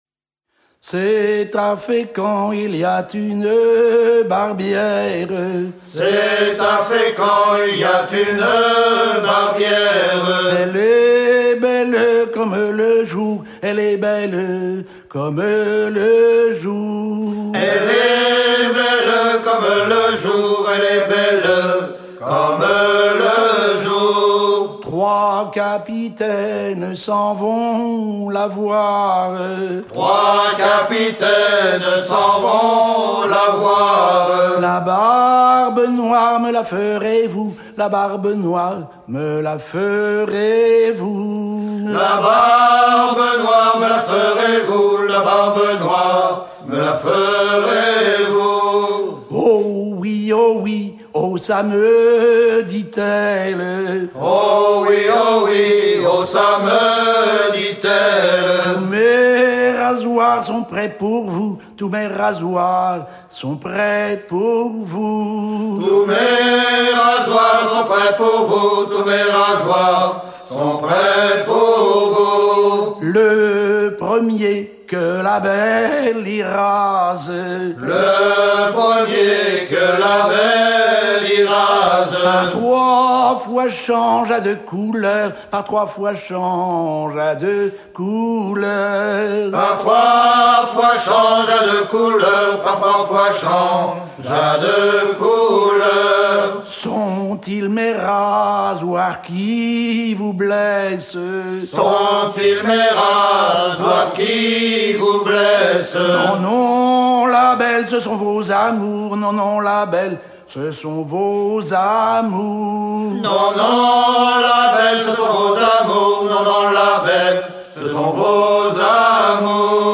danse : ronde à trois pas
Pièce musicale éditée